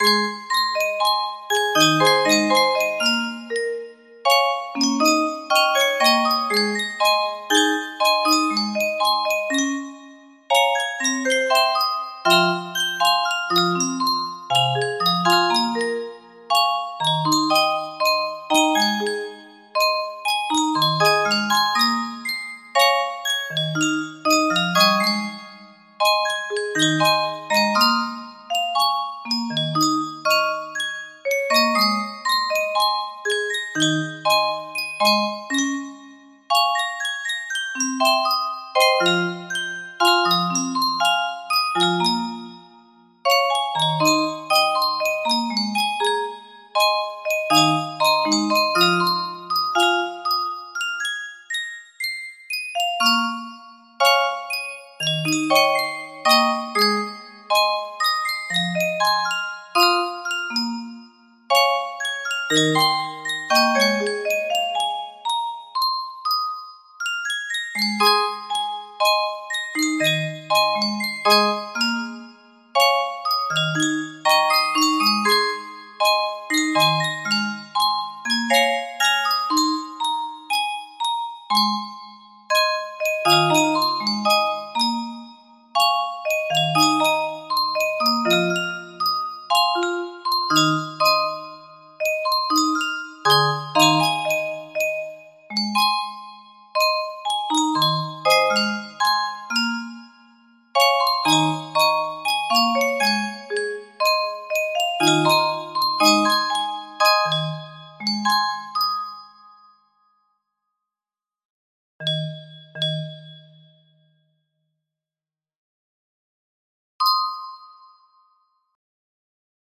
Full range 60
Traditional Japanese stringed musical instrument